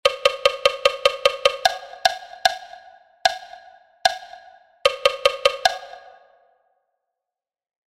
motivo_ritmico_2.mp3